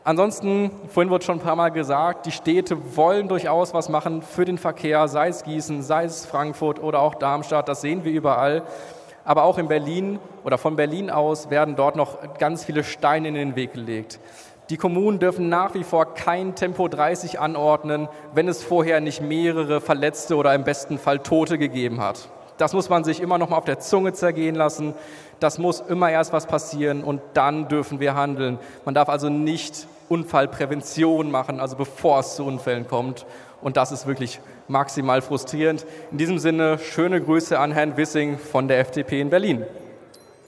Bei meiner Rede gestern auf der konnte ich es mir nicht verkneifen unseren Bundesautominister Wissing in Berlin zu grüßen.